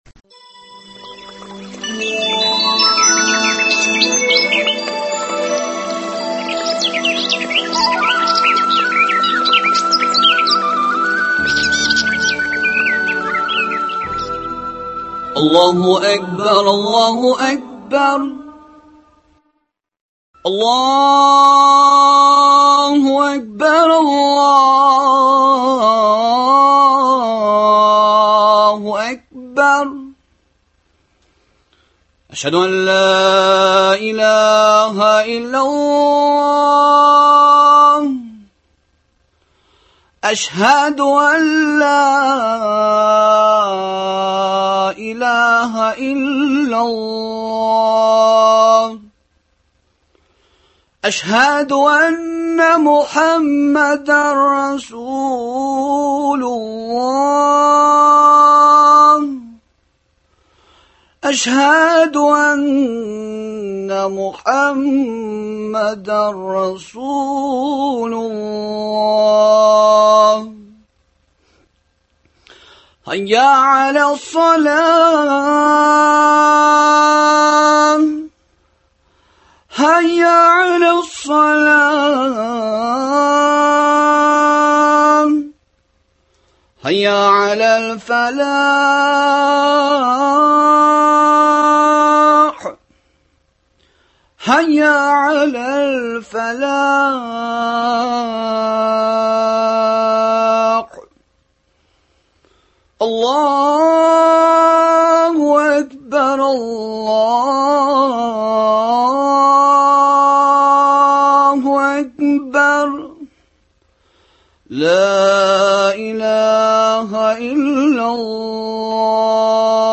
мәхәббәт, аның төрләре, аның үзенчәлекләре һәм нигезендә Аллаһы Тәгалә хаклары торган хакыйкате турында әңгәмә.